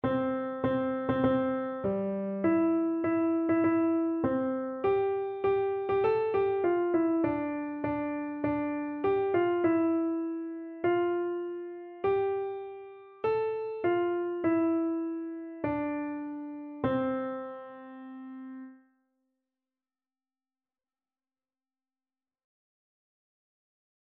No parts available for this pieces as it is for solo piano.
4/4 (View more 4/4 Music)
Piano  (View more Beginners Piano Music)